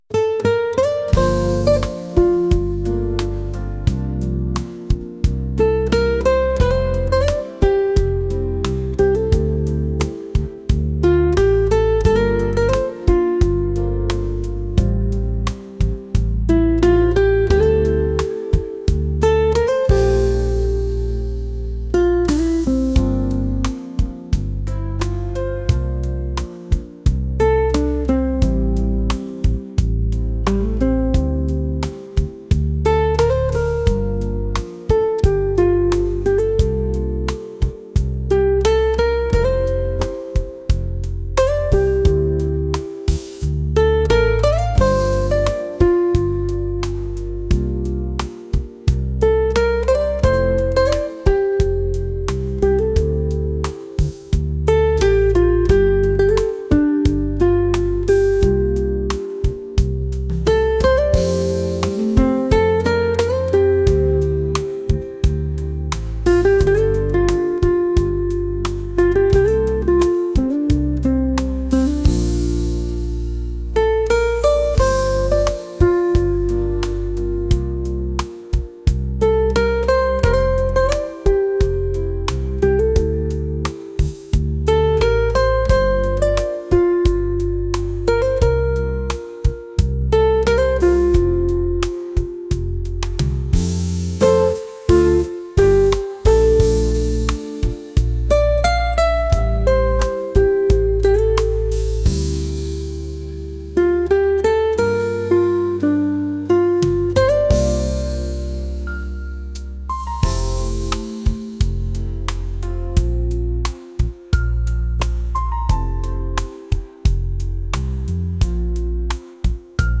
romantic | smooth | jazz